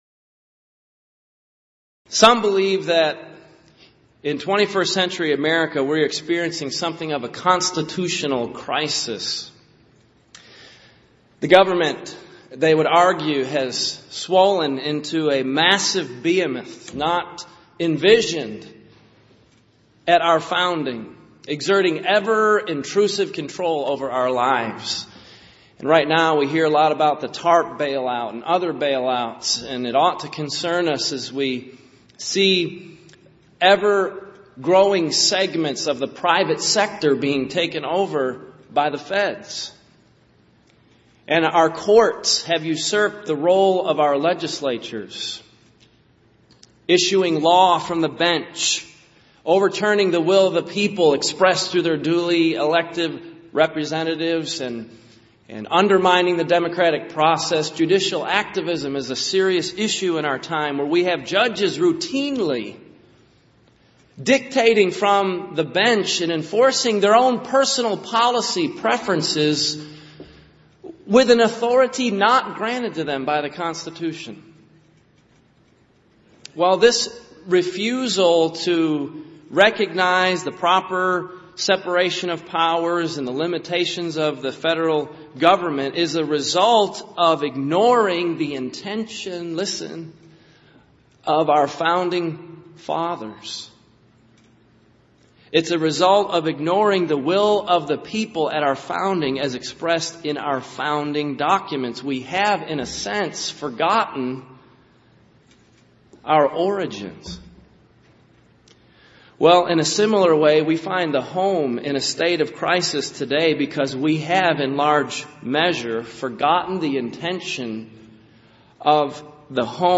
Event: 28th Annual Southwest Lectures Theme/Title: Honoring Christ: Calling For Godly Homes
lecture